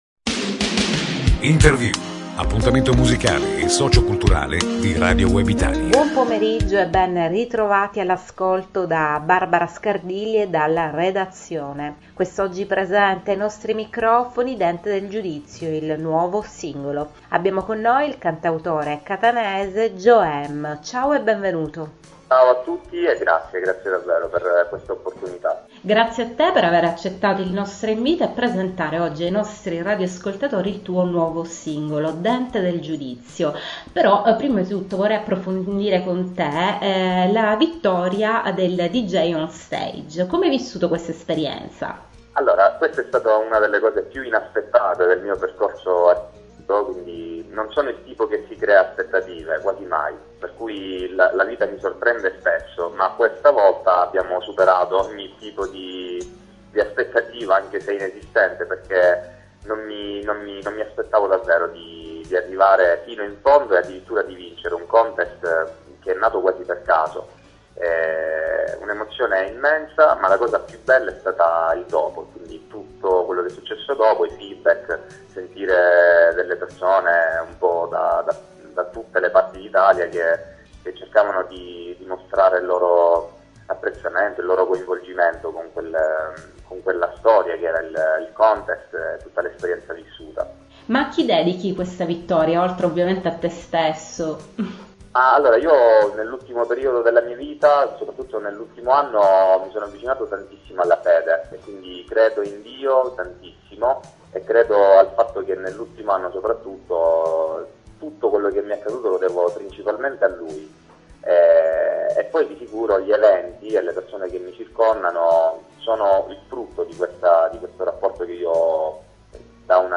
l’intervista per il nuovo singolo e video “Dente del Giudizio”